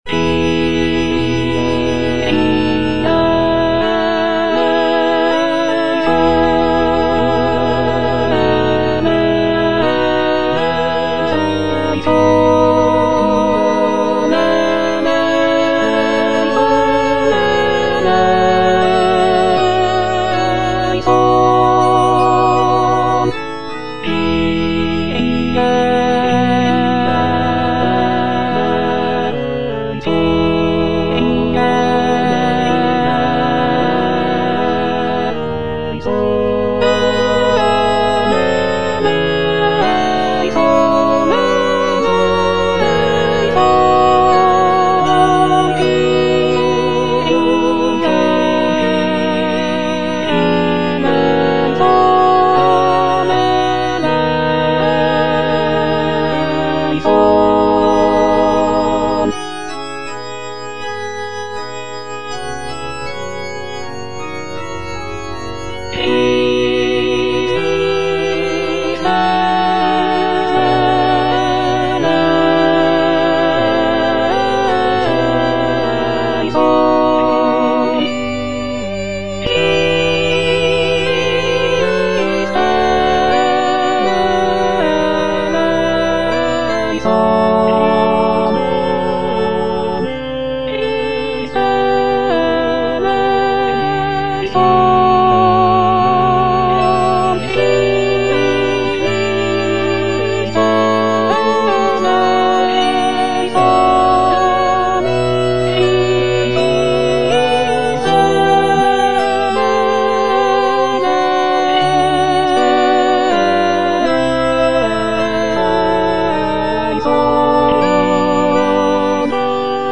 J.G. RHEINBERGER - MISSA MISERICORDIAS DOMINI OP.192 Kyrie - Alto (Emphasised voice and other voices) Ads stop: auto-stop
Completed in 1887, it is a mass setting for mixed choir, soloists, and orchestra.